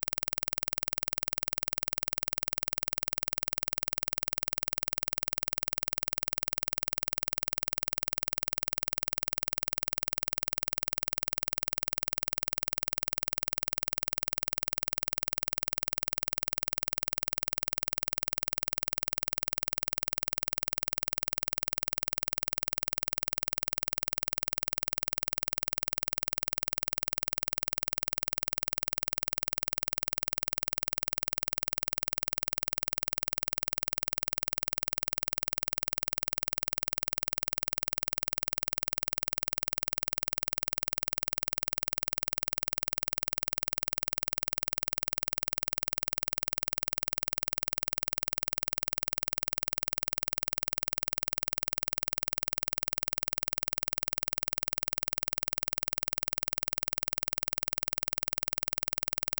Click track
Test signal to simulate vocal fry:
Plays on left channel only:
impulse-train-20-left.flac